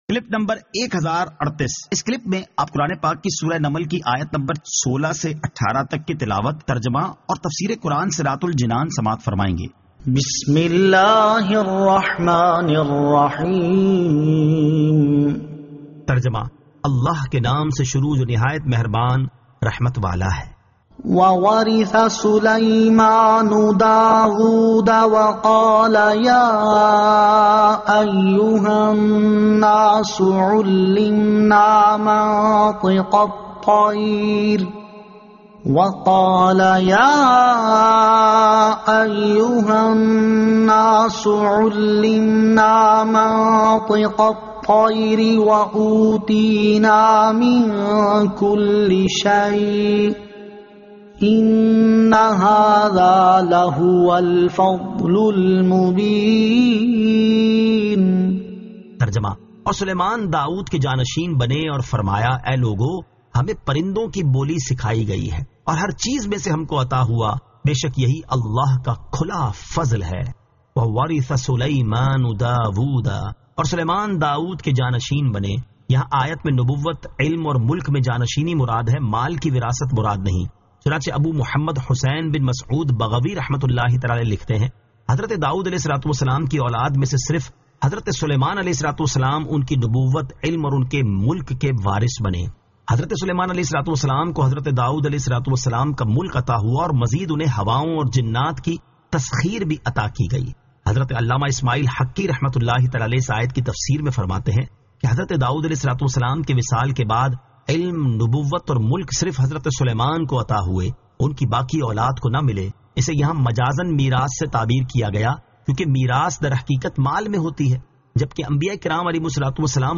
Surah An-Naml 16 To 18 Tilawat , Tarjama , Tafseer